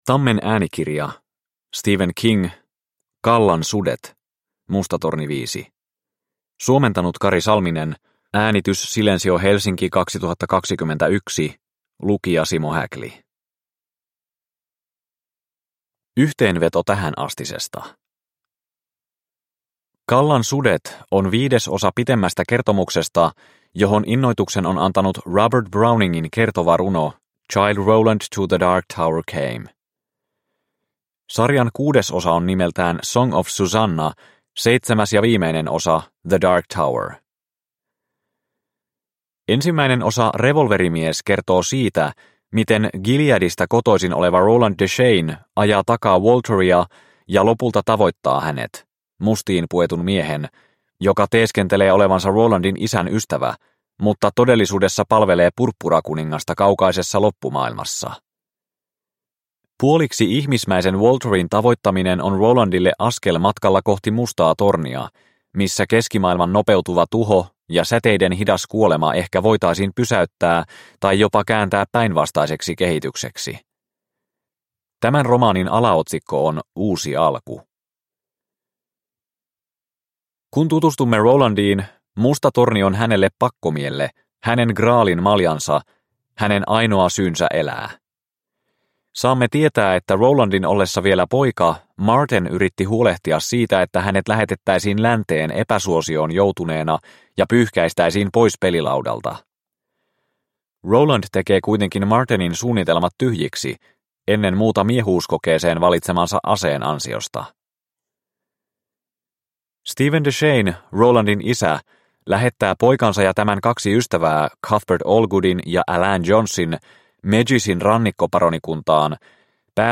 Callan sudet – Ljudbok – Laddas ner